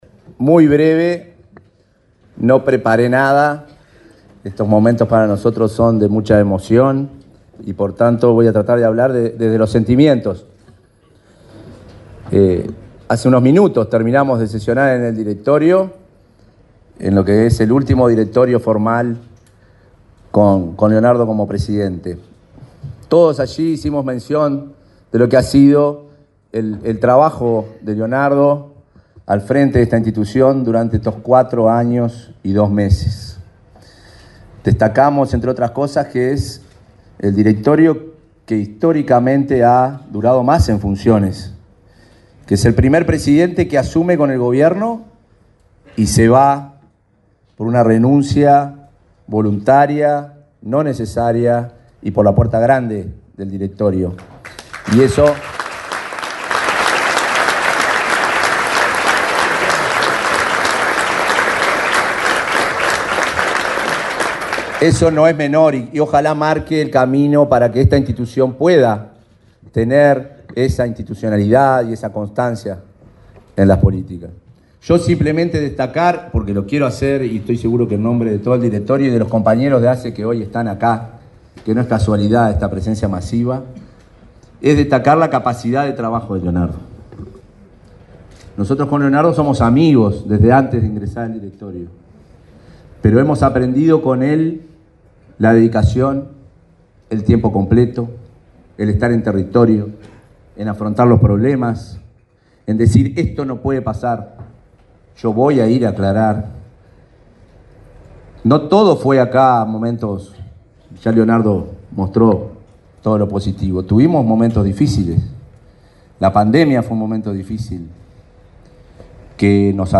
Palabras del vicepresidente de ASSE, Marcelo Sosa
El vicepresidente de ASSE, Marcelo Sosa, quien asumirá la presidencia del organismo ante la renuncia de Leonardo Cipriani, participó del acto de